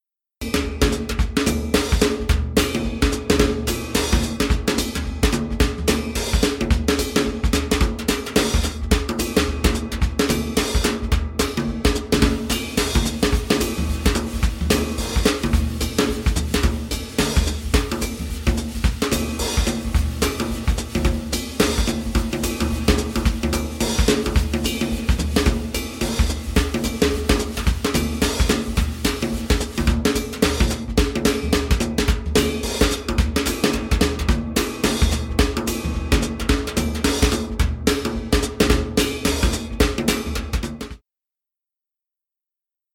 柔和温暖的架子鼓
灰尘、烟雾和温暖
刷子，但不仅仅是爵士乐。还有棒，木槌，茶棒：任何能使攻击变得柔和并允许纹理唱出来的东西。
Moonkits是故意在低速下录制的额外细节，因为不是每首曲目都是摇滚乐。
《月亮小子》是在伦敦北部的Konk录音室录制的，该工作室由金克斯的雷·戴维斯创立并仍然拥有。